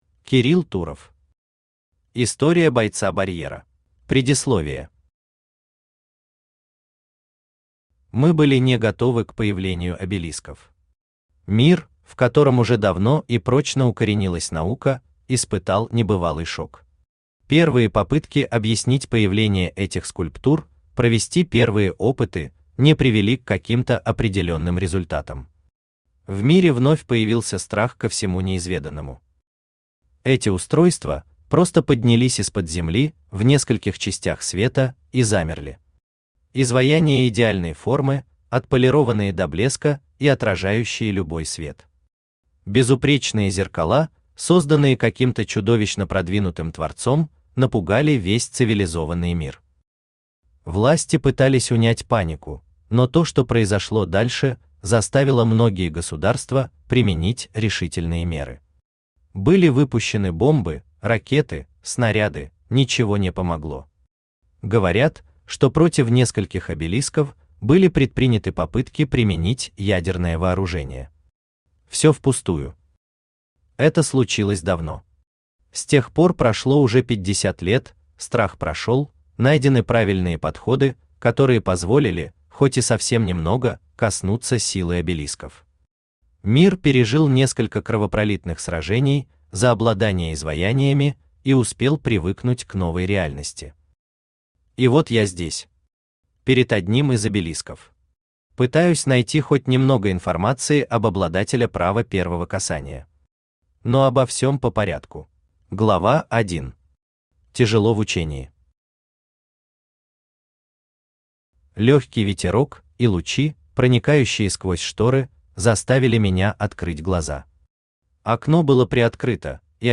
Аудиокнига Обелиски.
Aудиокнига Обелиски. История бойца-барьера Автор Кирилл Туров Читает аудиокнигу Авточтец ЛитРес.